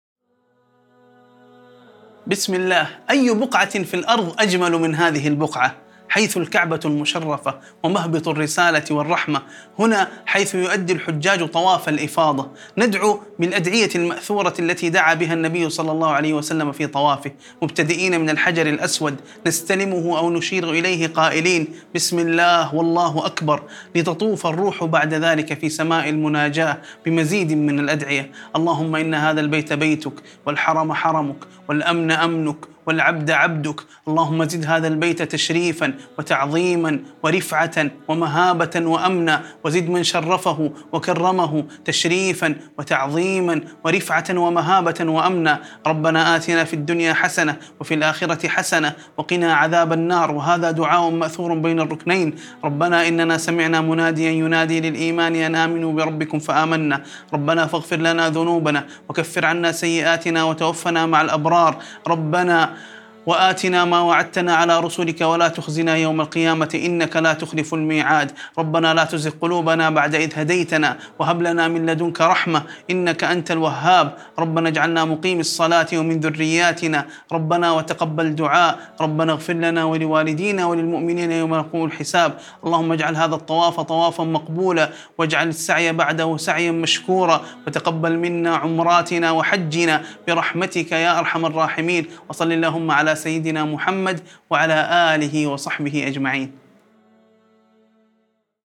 دعاء خاشع أثناء الطواف حول الكعبة المشرفة، يتضمن مناجاة الله تعالى وتعظيم بيته الحرام، والطلب من الله القبول والمغفرة والرحمة للداعي ولوالديه وللمؤمنين.